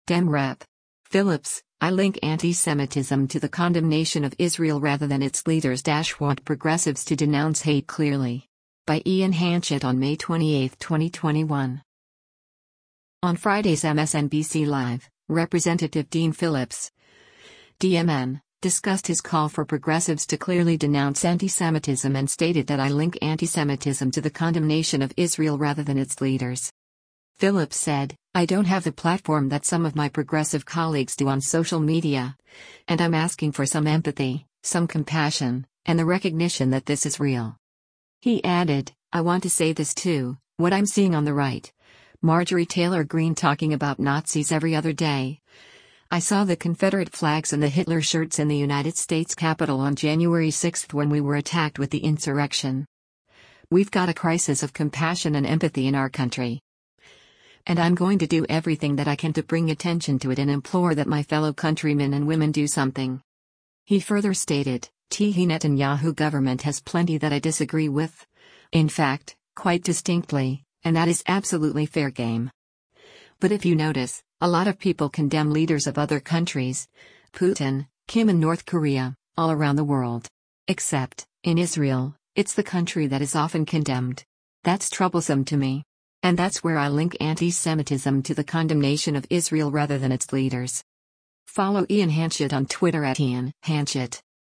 On Friday’s “MSNBC Live,” Rep. Dean Phillips (D-MN) discussed his call for progressives to clearly denounce antisemitism and stated that “I link antisemitism to the condemnation of Israel rather than its leaders.”